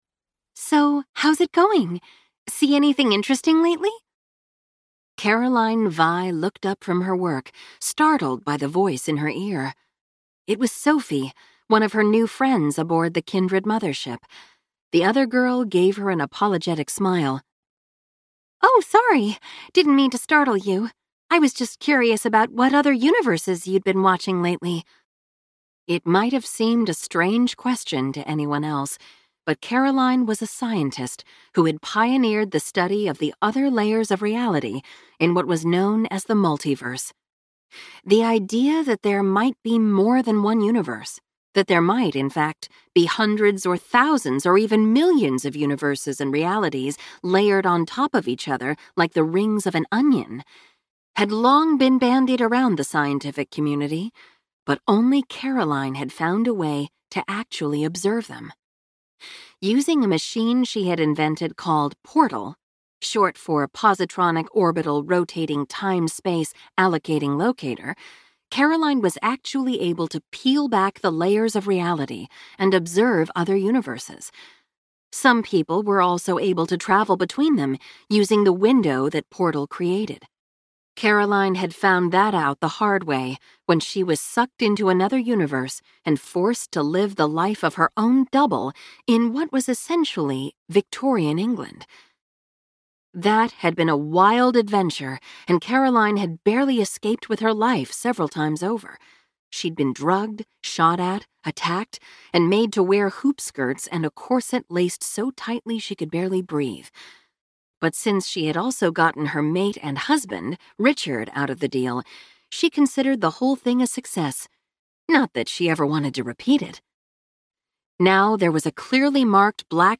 Time to Heal audiobook